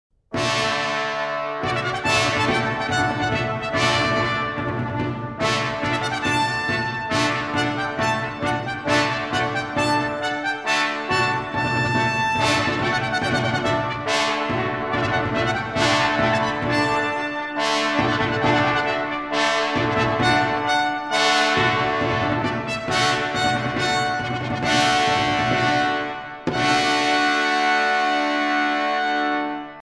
Zo liet hij aan de opera een instrumentale introductie in de vorm van een toccata voorafgaan. Volgens de componist moest deze introductie driemaal worden gespeeld voordat het toneel doek werd geopend. Hij schreef hiermee in feite de eerste ouverture in de operageschiedenis.
Toccata_Orfeo_Monteverdi.mp3